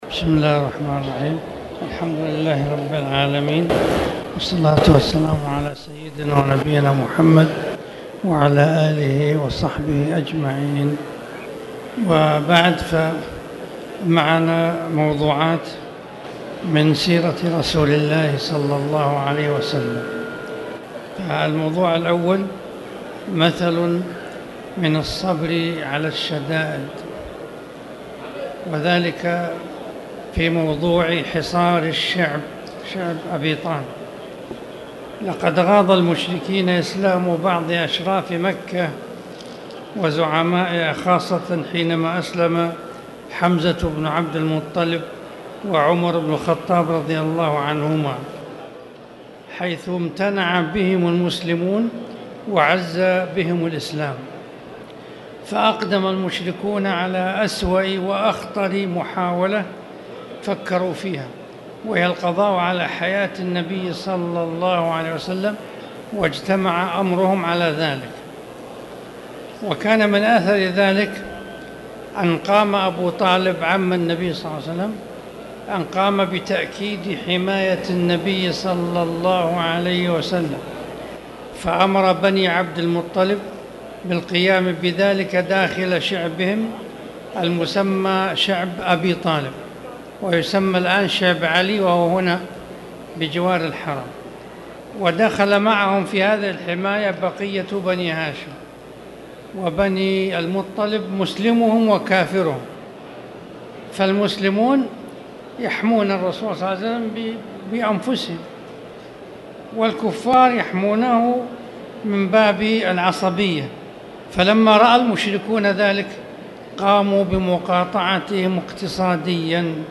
تاريخ النشر ٤ شعبان ١٤٣٨ هـ المكان: المسجد الحرام الشيخ